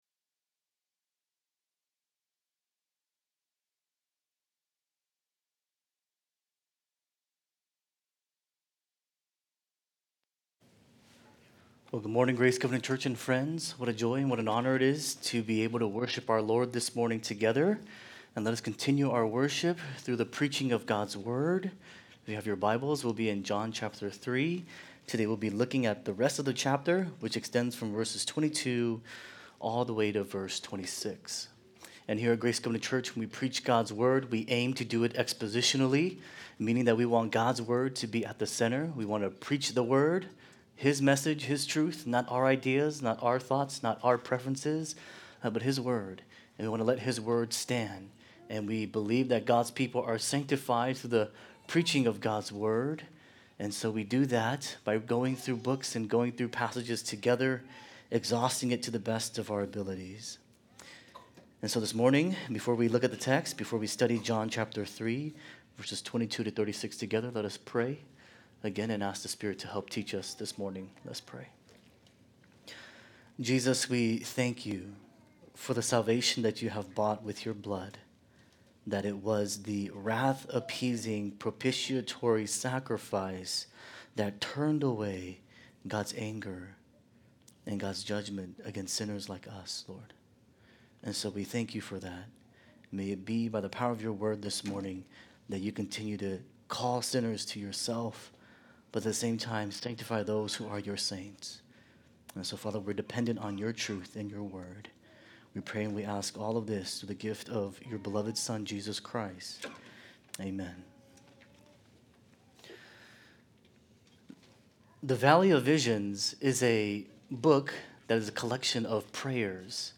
Sermons | Grace Covenant Church